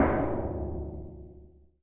Metal Barrel Cong
描述：Hitting a metal barrel. Sounds a bit like a cong. Recorded with Shure SM 58.
标签： cong metal barrel percussion hit
声道立体声